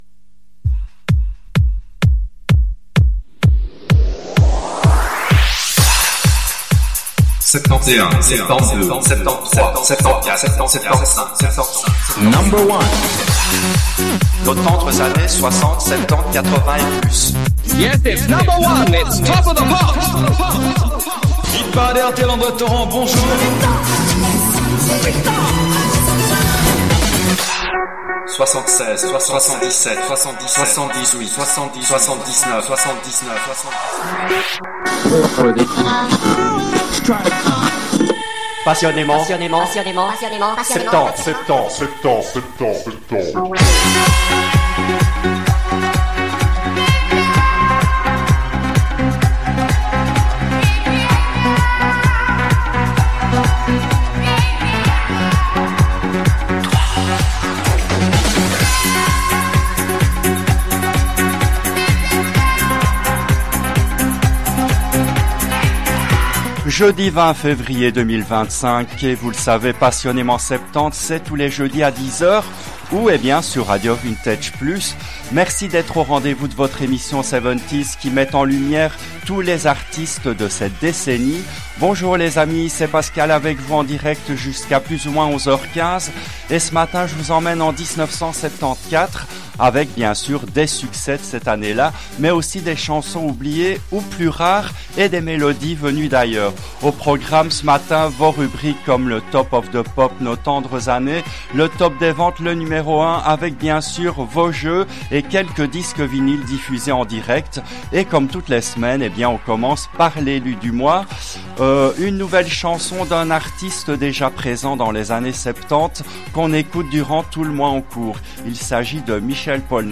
Cette émission a été diffusée en direct le jeudi 08 décembre 2022 à 10h, depuis les studios belges de RADIO VINTAGE PLUS.